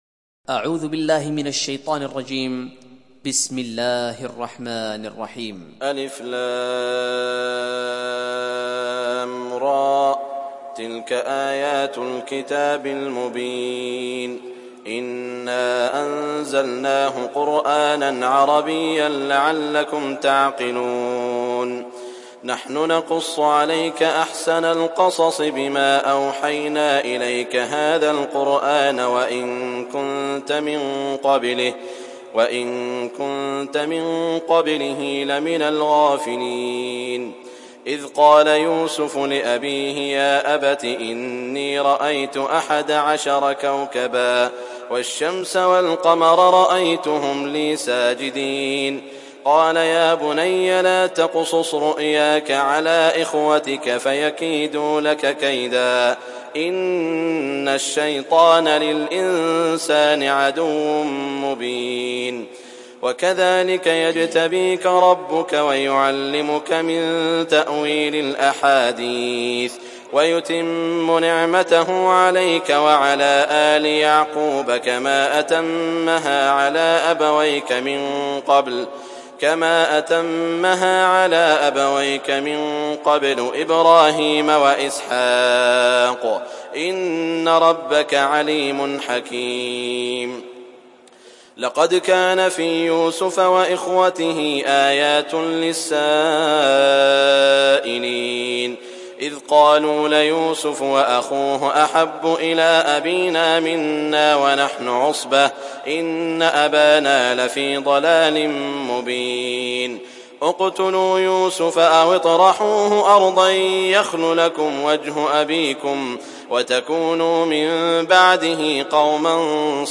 Sourate Yusuf Télécharger mp3 Saud Al Shuraim Riwayat Hafs an Assim, Téléchargez le Coran et écoutez les liens directs complets mp3